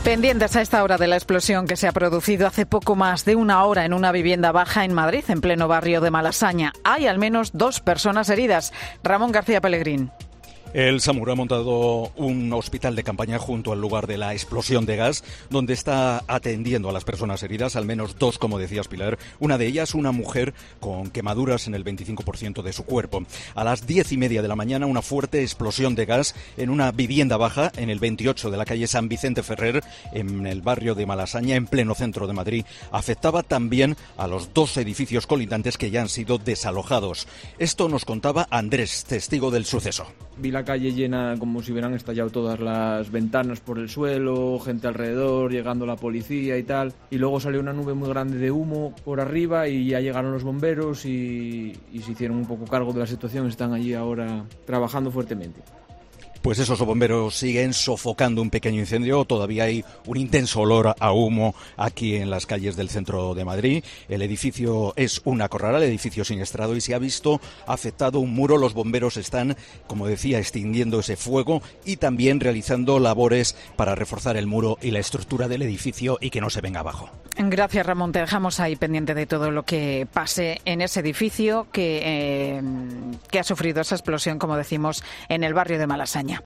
COPE informa de una fuerte explosión de gas en el centro de Madrid, en el barrio de Malasaña